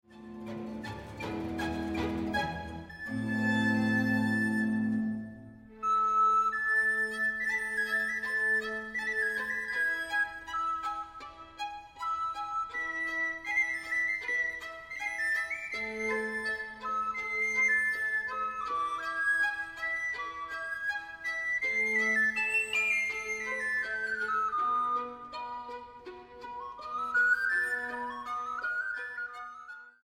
para flauta sopranino, cuerdas y contínuo